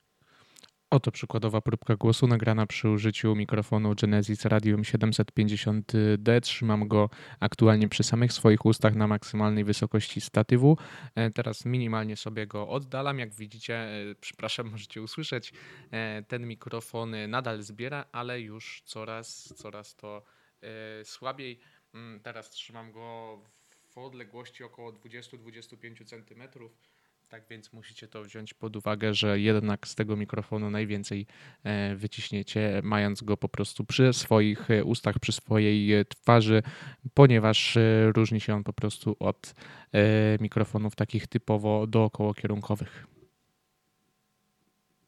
Genesis Radium 750 to mikrofon dynamiczny, jednokierunkowy, czyli kardioidalny.
Sam dźwięk przechwytywany przez Radium 750D jest czysty.
Nasz głos jest ponad wyraźny, a otoczenie praktycznie nieprzechwytywane.
Przykładowe próbki głosu prezentuje się następująco: